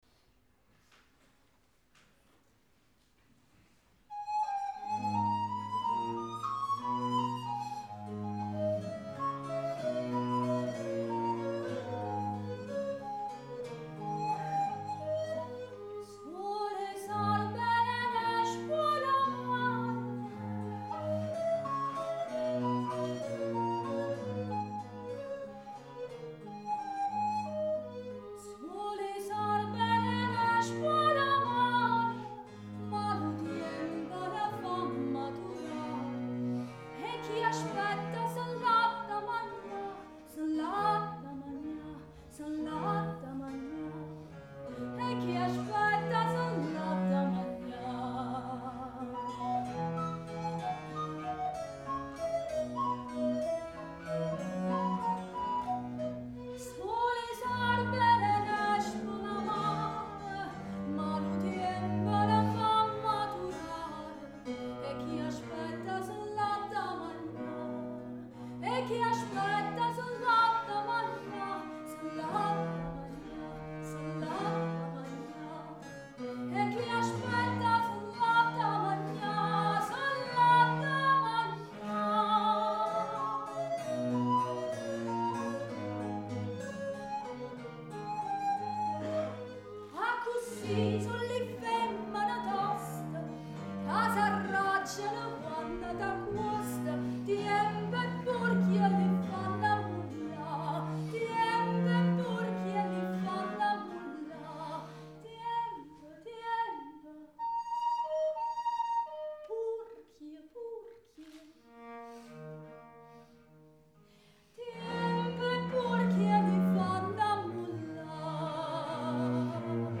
Antica Consonanza
Registrazioni dal vivo di alcuni concerti del Festival di Musica Antica di Salerno